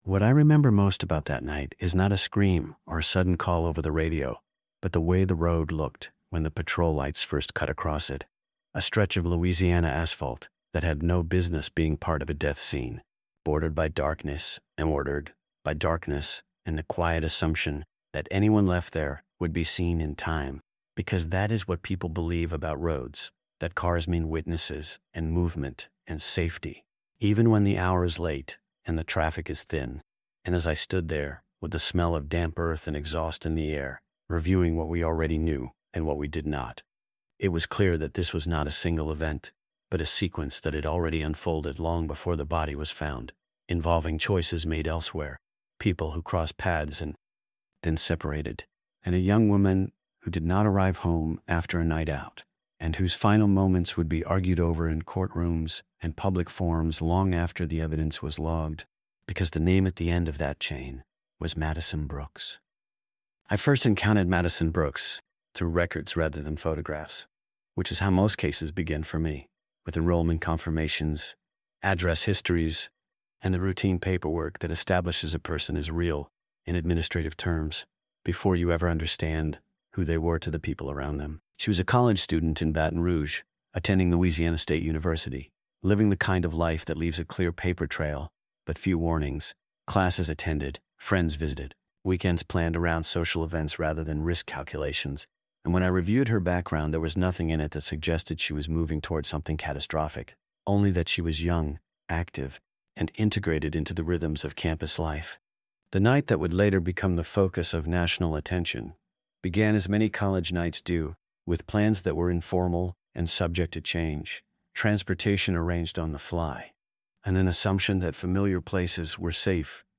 Told from the perspective of a first-person detective narrator, the story reconstructs the verified sequence of events using documented evidence, including witness statements, digital records, medical findings, and investigative timelines.